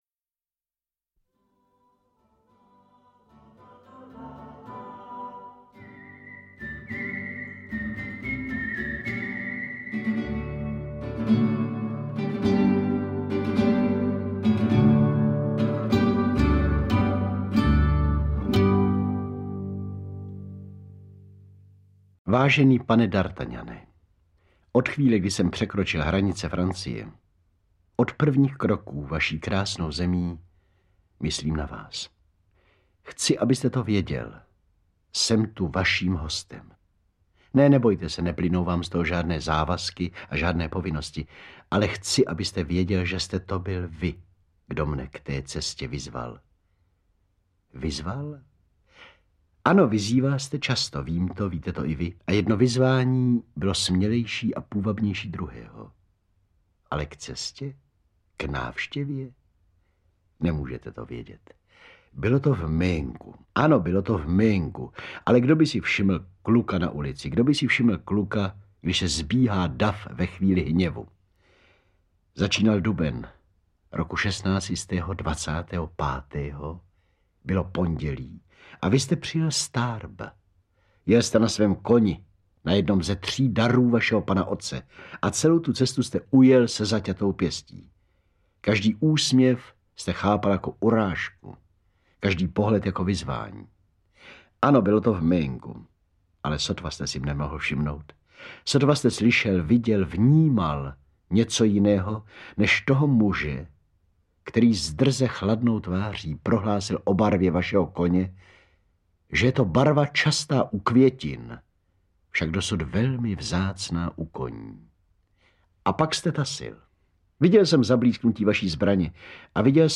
Interpret:  Miroslav Horníček